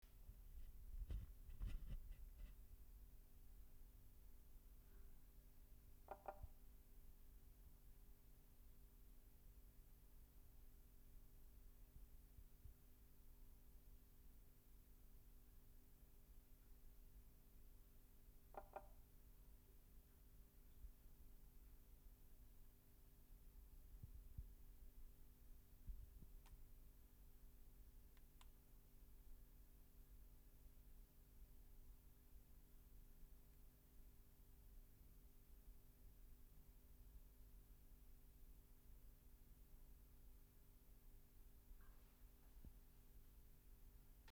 Especie: Pelobates fuscus
Órden: Anura Clase: Amphibia
Localidad: Romania: Danube Delta, north of Constanta
Naturaleza: Laboratorio Temperatura: aire 25ÝC, agua 22ÝC